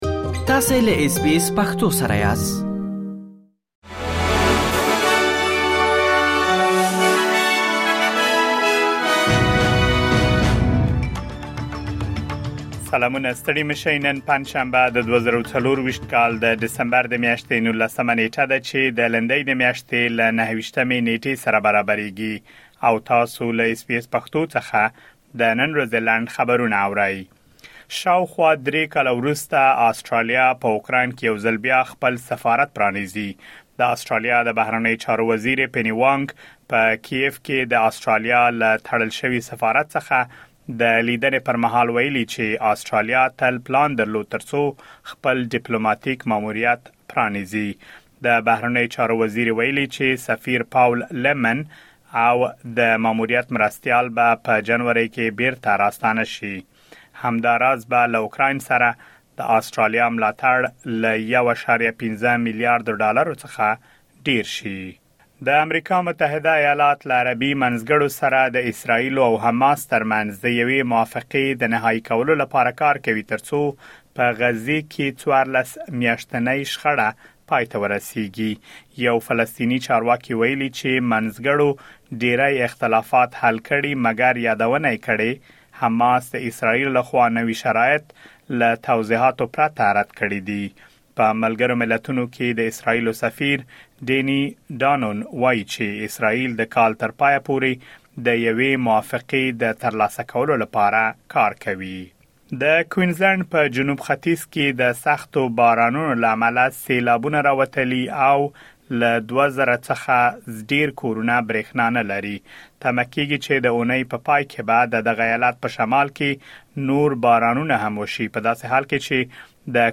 د اس بي اس پښتو د نن ورځې لنډ خبرونه |۱۹ ډسمبر ۲۰۲۴